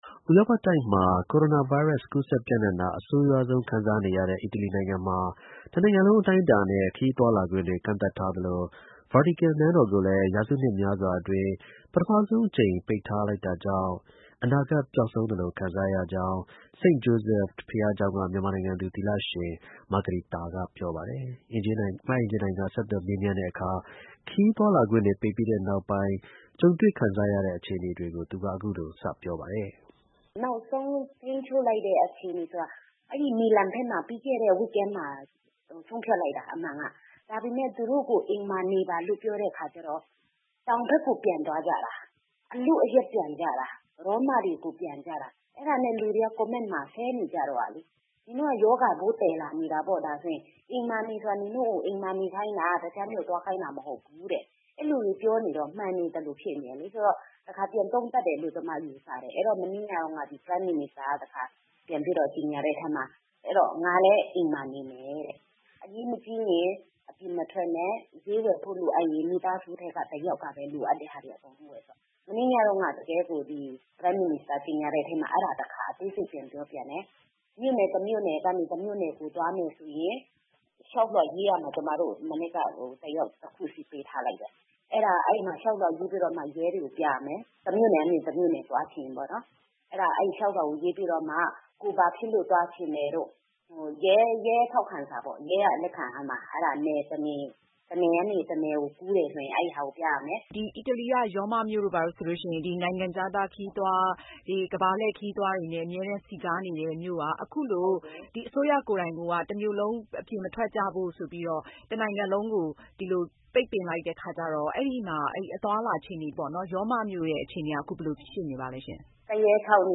ဆက်သွယ်မေးမြန်းထားတာပါ။